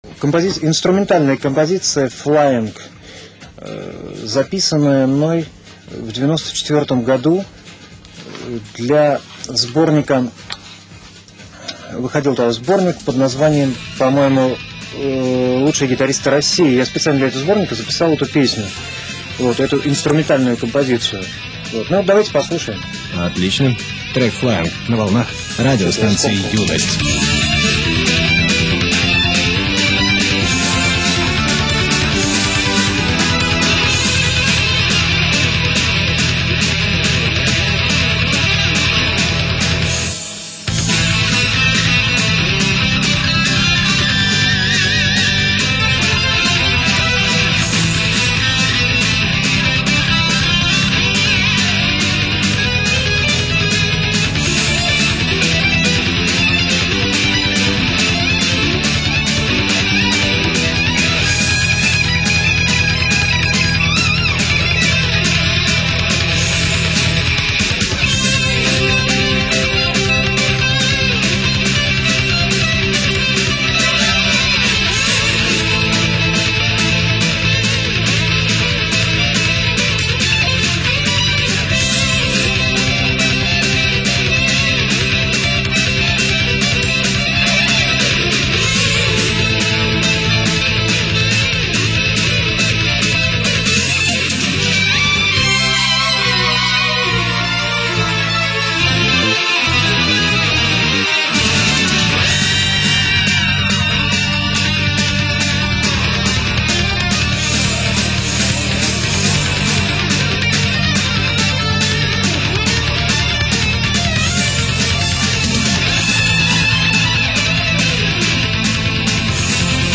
инструментальная композиция